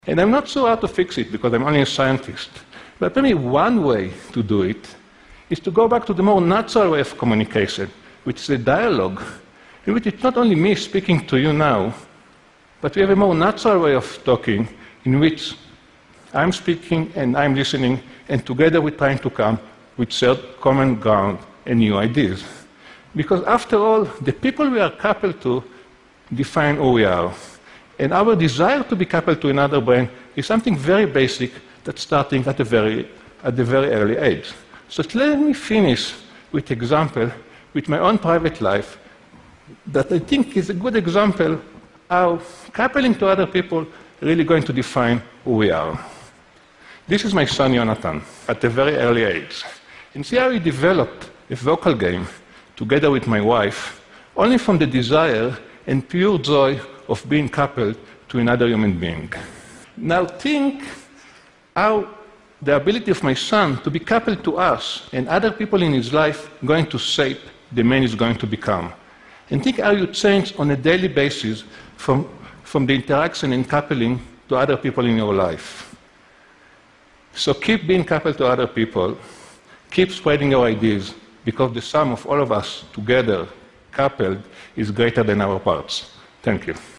TED演讲:我们的大脑是如何交流的?(10) 听力文件下载—在线英语听力室